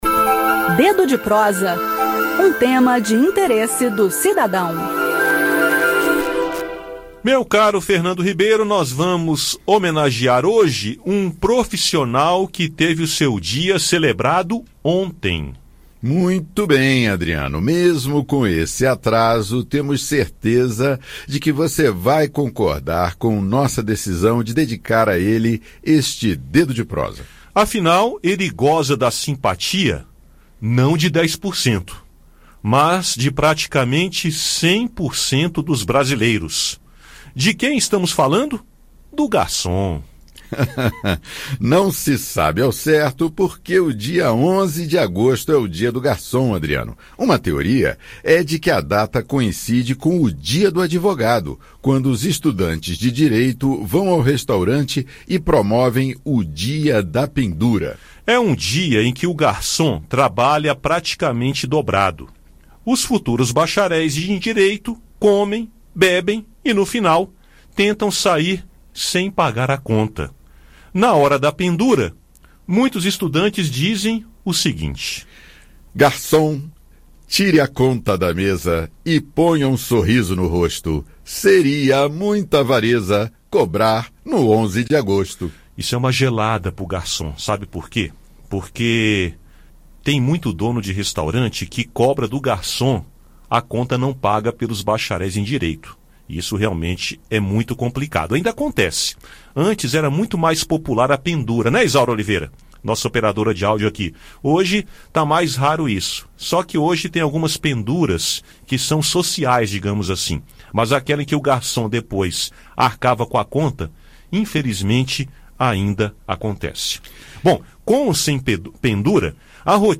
No "Dedo de Prosa" de hoje, segunda-feira (12), o assunto é o Dia do Garçom, comemorado em 11 de agosto. Ouça o áudio com o bate-papo e com nossa homenagem.